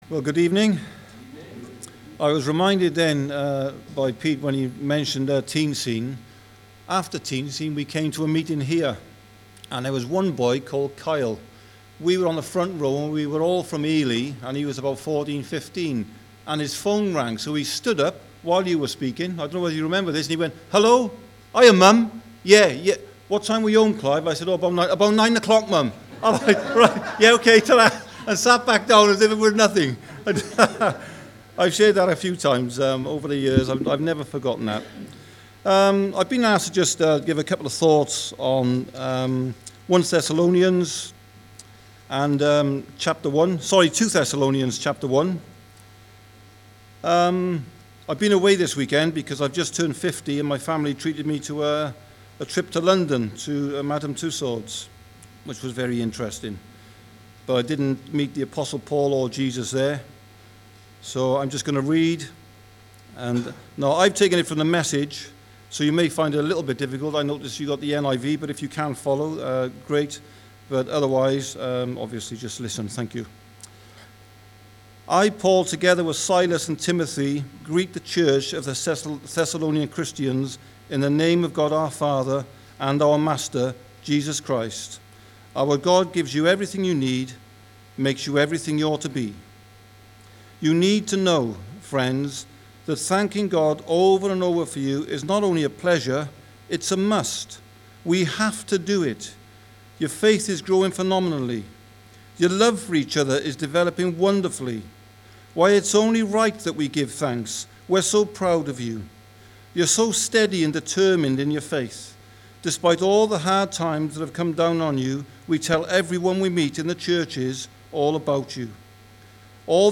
2 Thessalonians 1 Service Type: Sunday Evening « 1 Thessalonians 5